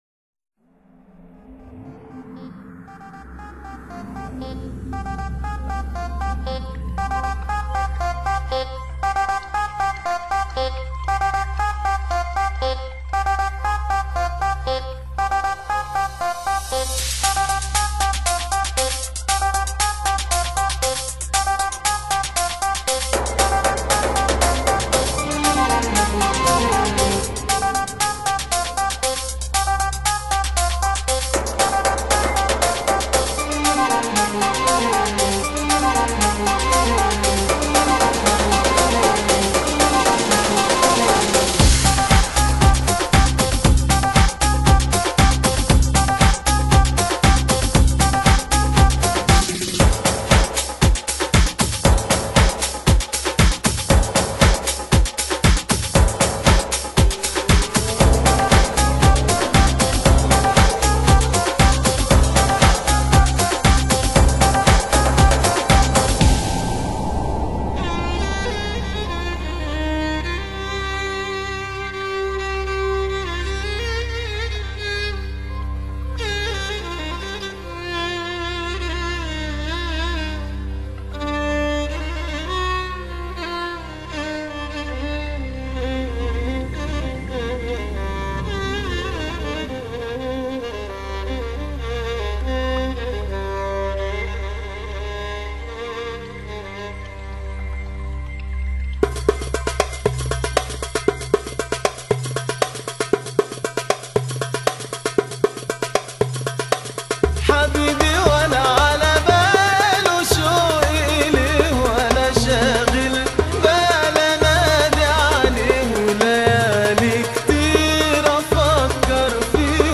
Genre: Arabic Music | World | Ethnic